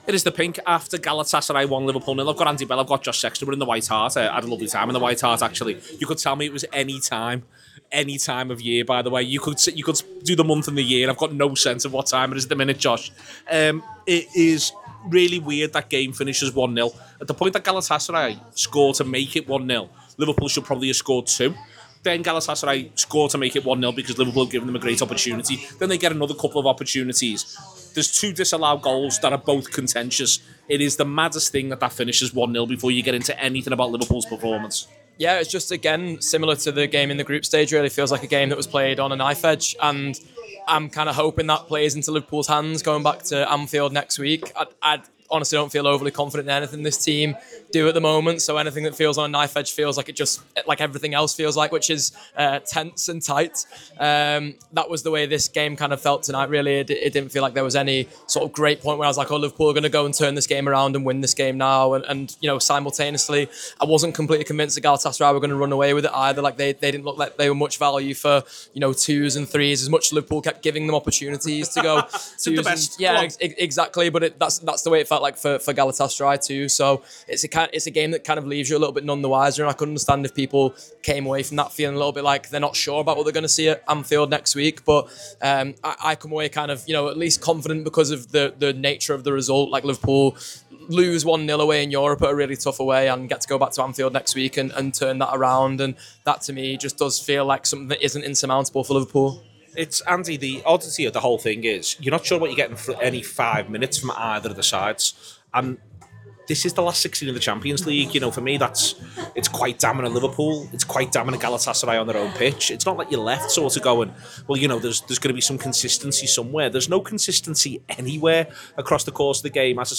The Anfield Wrap’s post-match reaction podcast after Galatasaray 1-0 Liverpool in the Champions League last 16.